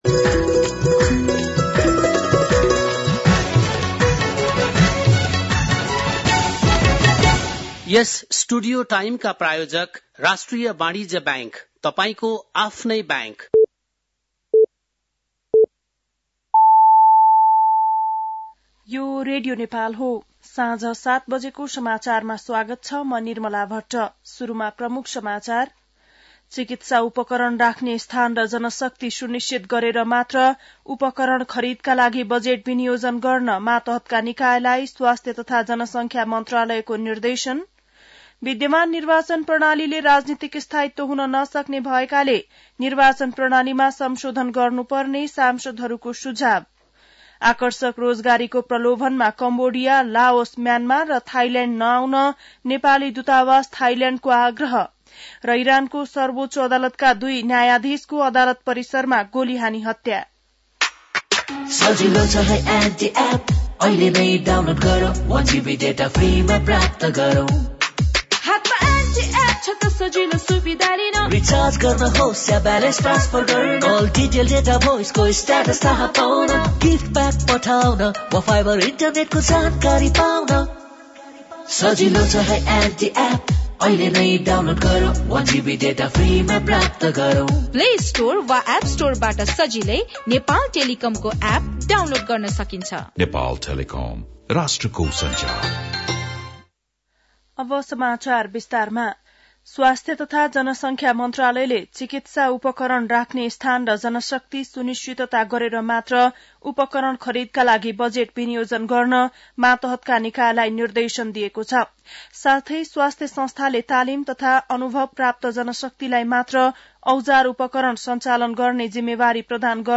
बेलुकी ७ बजेको नेपाली समाचार : ६ माघ , २०८१
7-PM-Nepali-NEWS-10-5.mp3